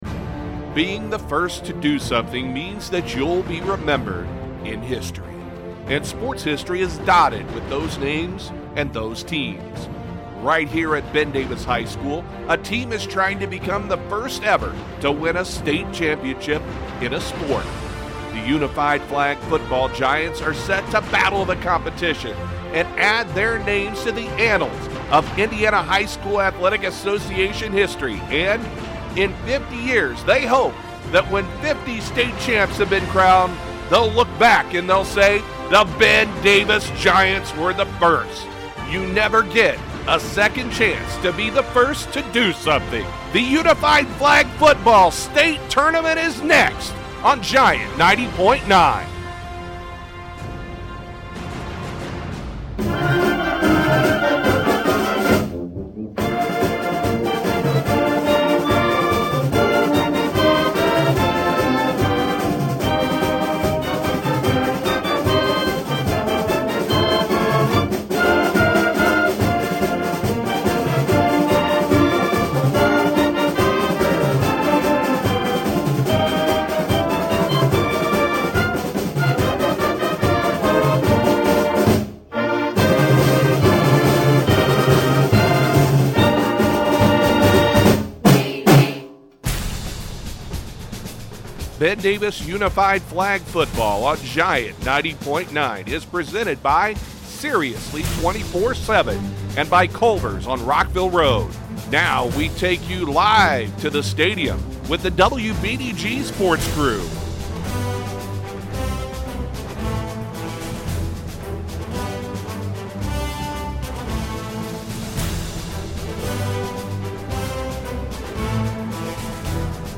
Game Open for the IHSAA Unified Flag Football Tournament
This is the open that we use for our Unified Flag Football Coverage (2018)